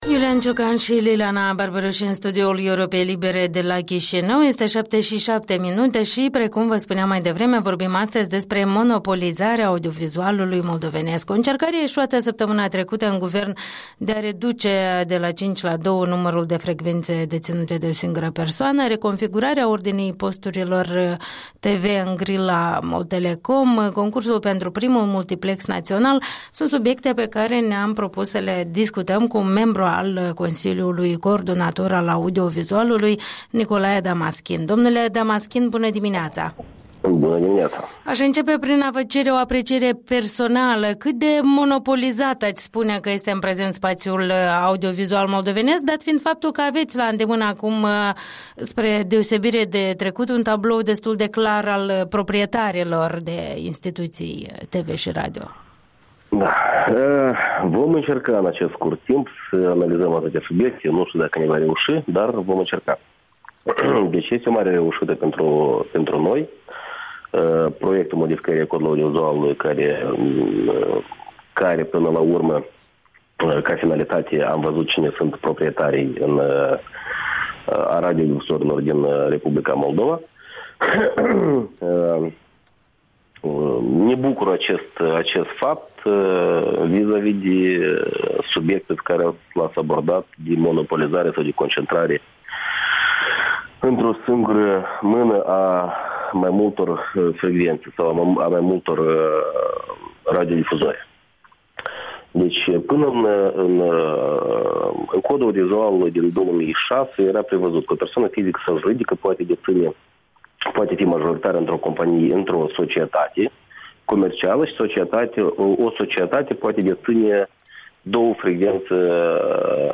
Interviul dimineții cu un membru al Consiliului Coordonator al Audiovizualului.
Interviul dimineții cu Nicolae Damaschin (CCA)